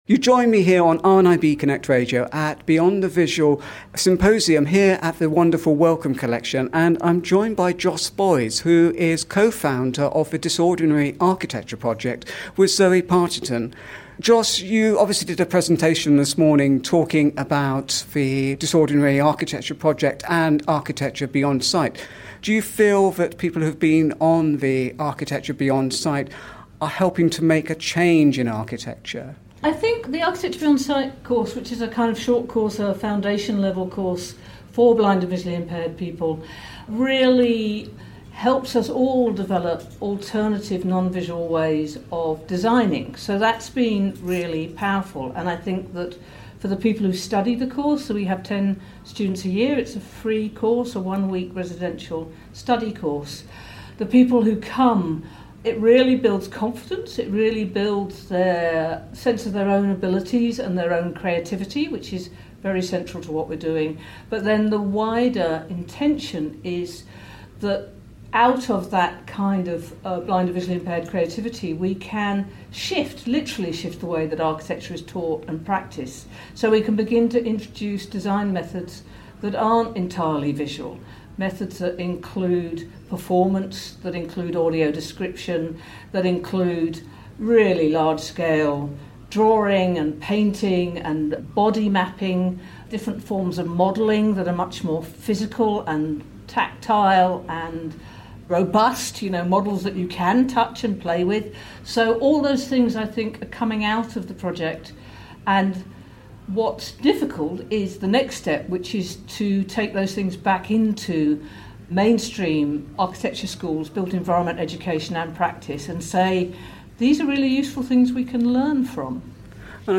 Beyond the Visual, a symposium reflecting on what blindness brings to the experience of art within cultural organisations and beyond was held at Wellcome Collection on Friday and Saturday 21 and 22 October 2022.